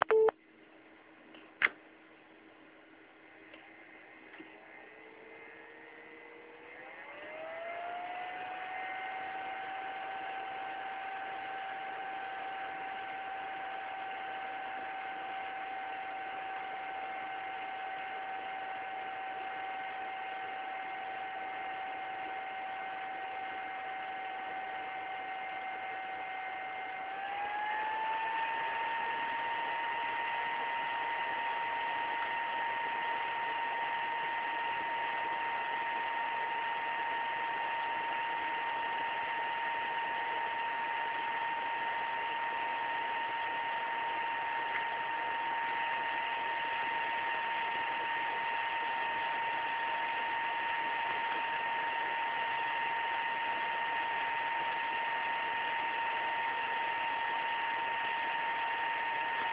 wycie chłodzenia powietrznego Radeona X1950XTX.